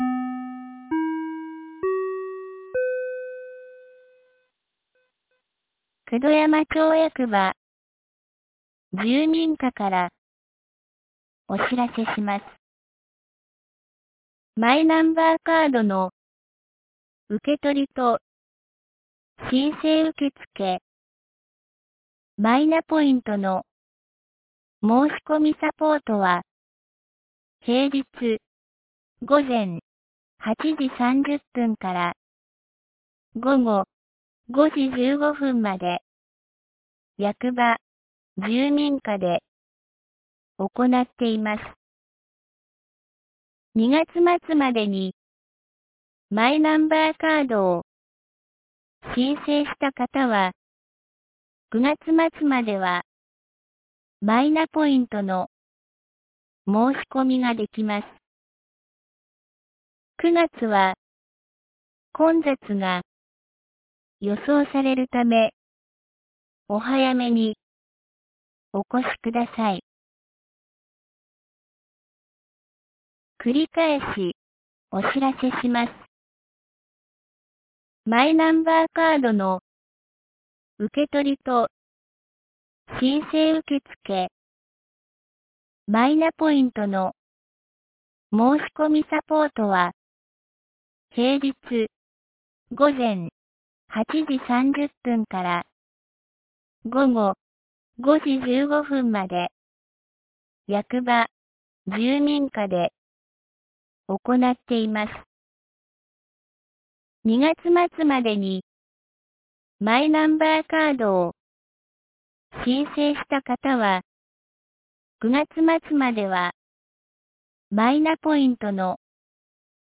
2023年07月04日 10時02分に、九度山町より全地区へ放送がありました。